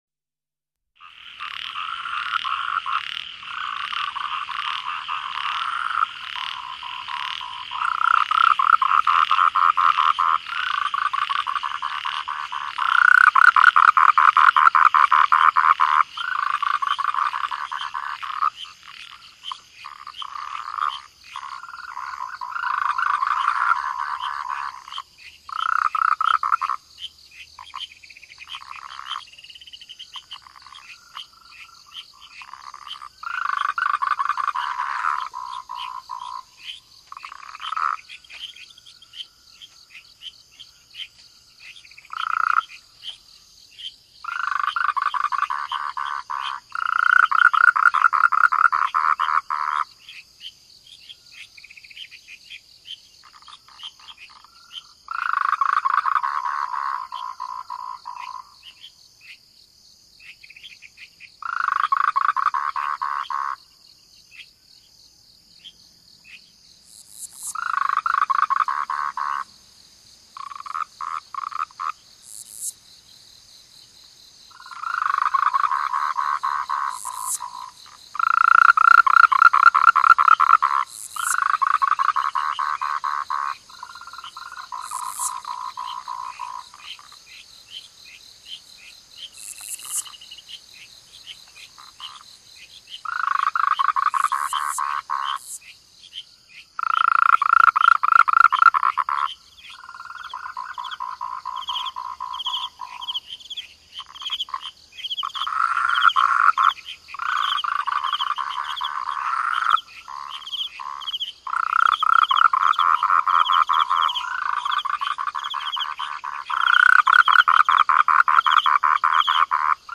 银色山岗－－纯自然声音